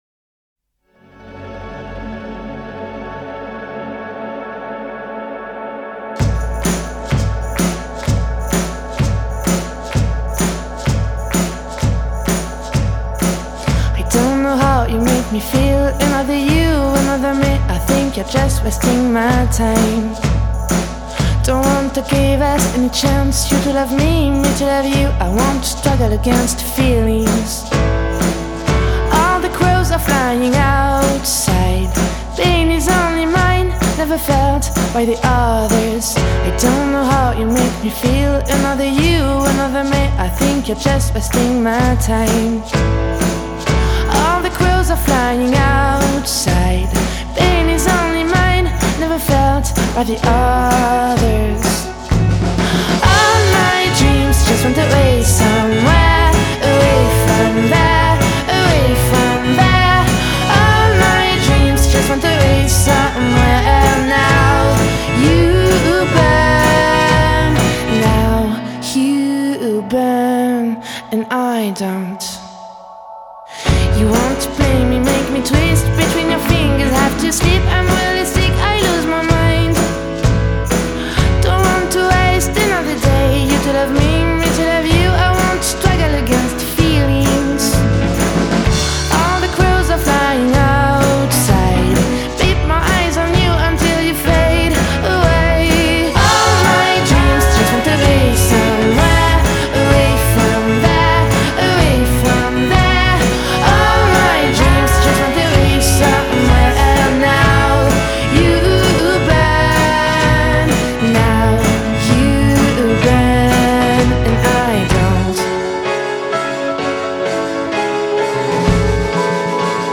Genre: Indiepop/Piano Rock/Female Vocal